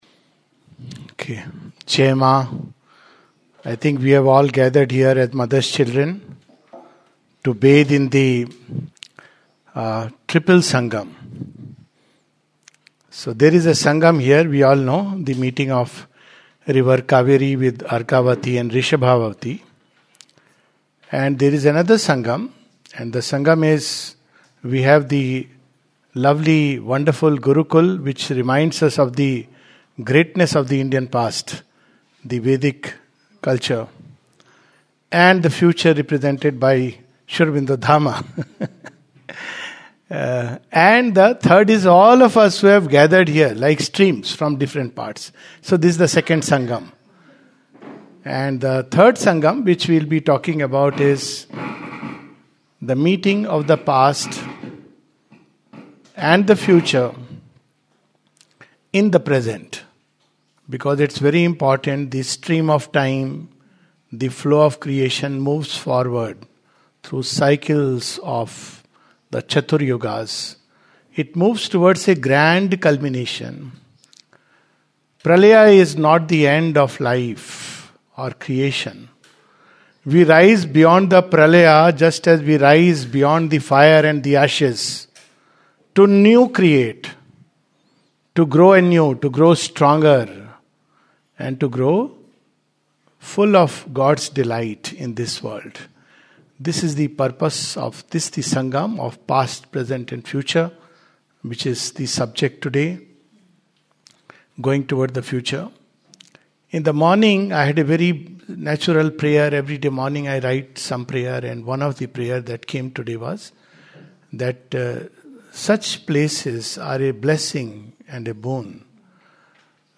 at the Sri Aurobindo Dham, Karnataka on August 30-31, 2025.